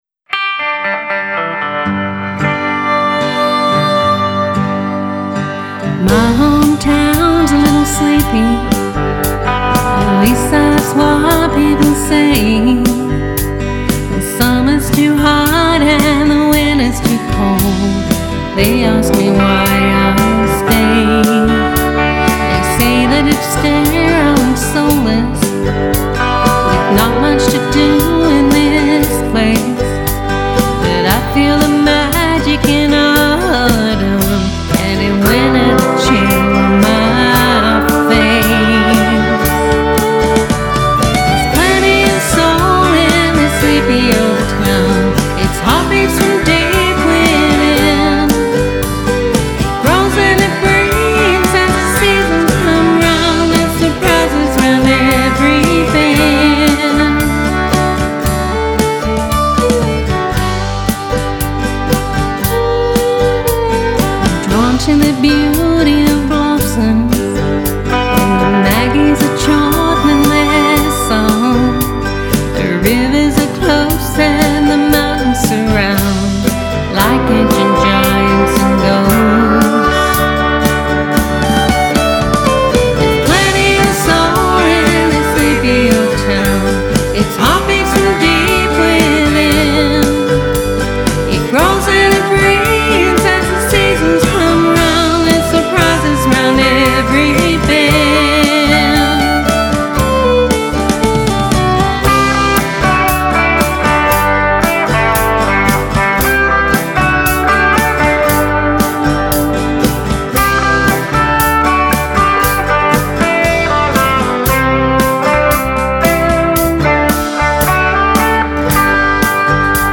Country duo
fiddle
an infectious country rock song